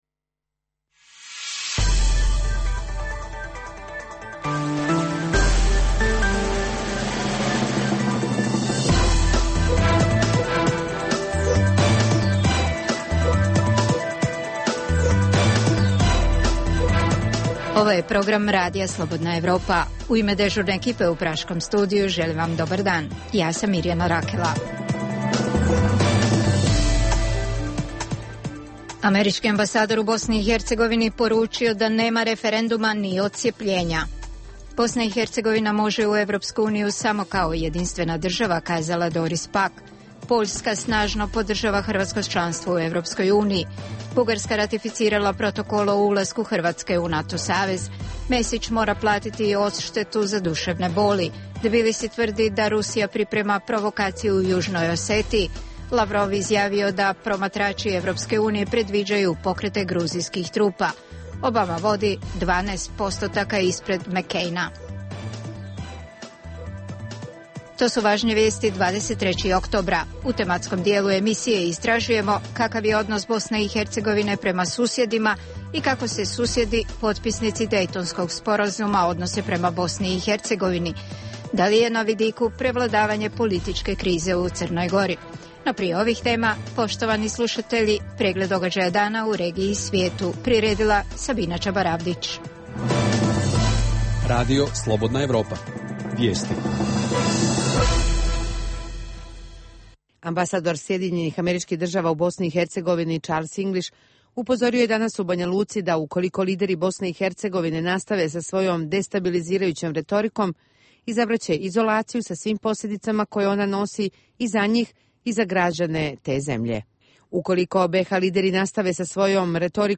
Kakav je odnos BiH prema susjedima i kako se susjedi, potpisnici Daytonskog sporazuma, odnose prema BiH. Objavljujemo razgovor sa finskom forenzičarkom Helenom Ranta koja je istraživala zločin u selu Račak na Kosovu 1999. i koja kaže da je ambasador William Walker, tada šef Misije OESS-a na Kosovu na nju vršio pritisak da kaže da je zločin nad Albancima počinila srpska strana. Na njene tvrdnje odgovara i ambasador Walker.